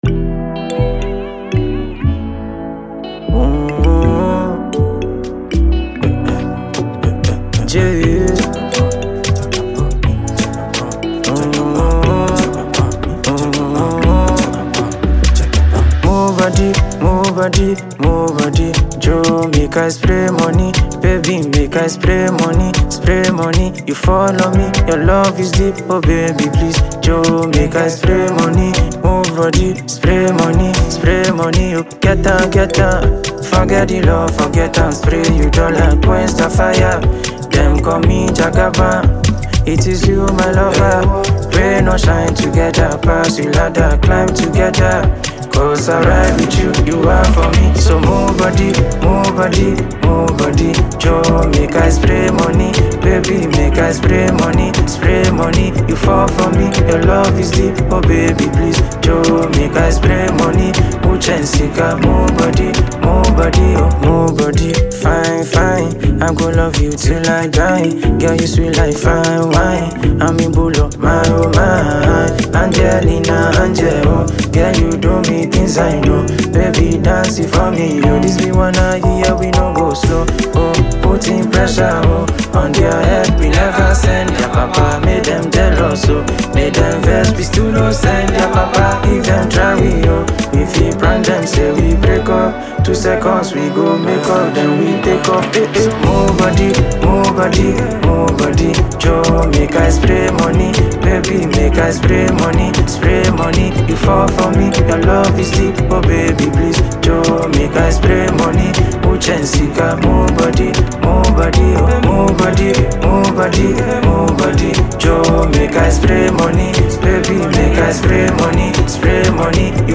nonstop energy, bouncy production, and dance-ready vibes